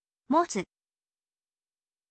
motsu, โมตสึ